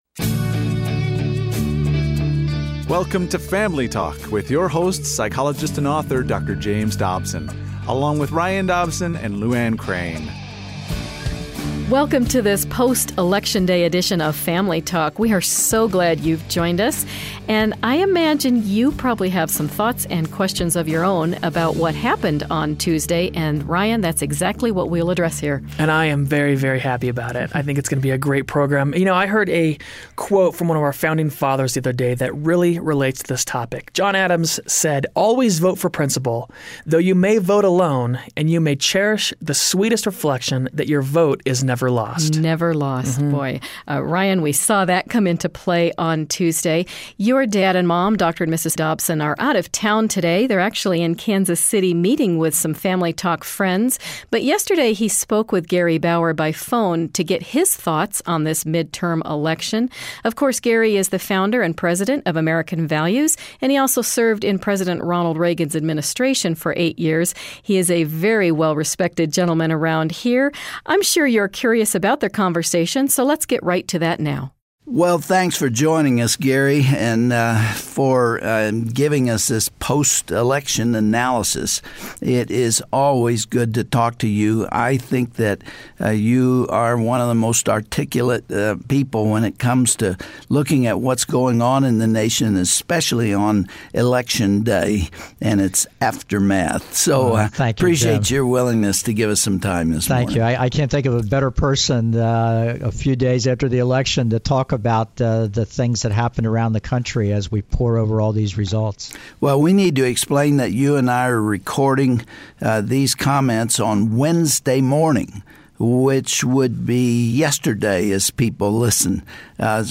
Dr. Dobson speaks with public policy expert, Gary Bauer, who weighs in on election outcomes and what it all means to the future of our country...